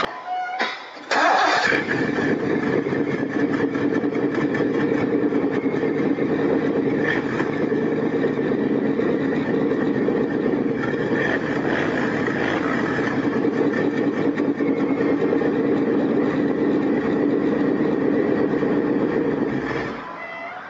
This really has a good sound, but then I have not heard a bad sounding 4.9. The intake system was put together with the stock air filter housing (with K&N filter) and the first couple of inches of the original Fiero air duct (Item A).
Want to hear what a 4.9 sounds like,
The sound of a V8.
FieroExh.aiff